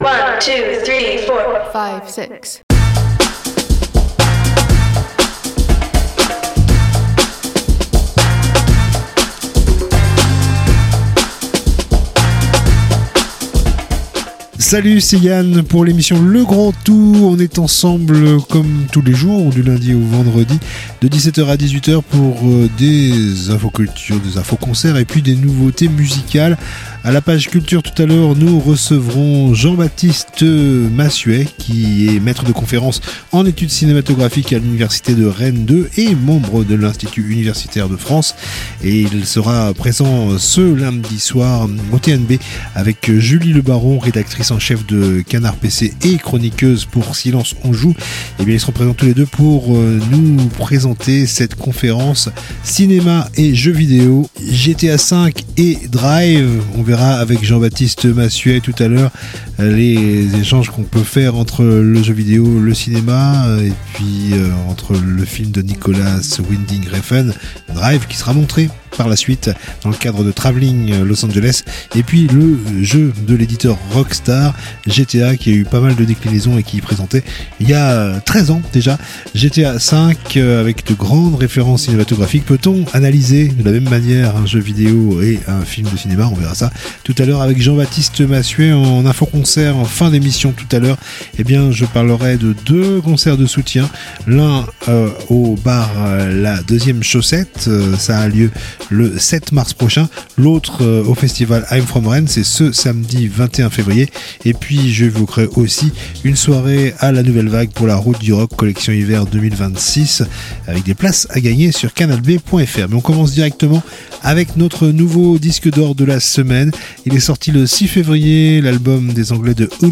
itv culture